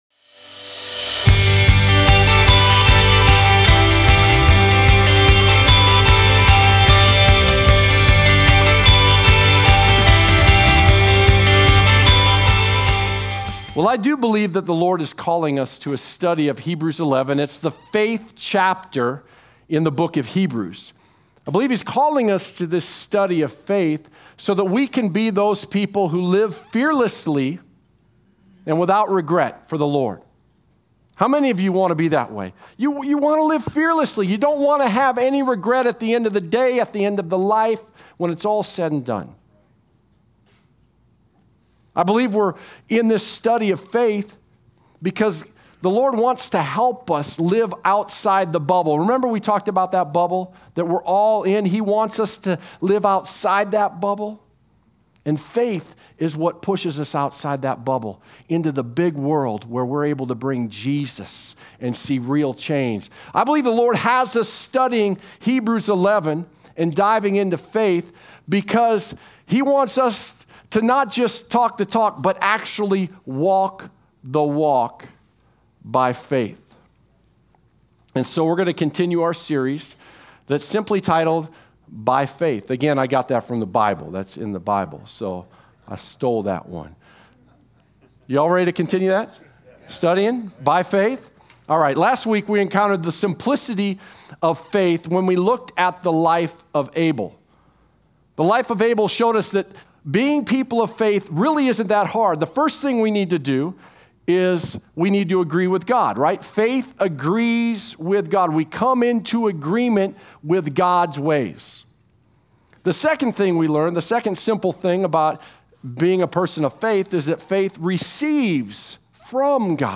Sermon Archive (2016-2022) - Evident Life Church | Gospel-Centered, Spirit Filled Church in Gilbert, AZ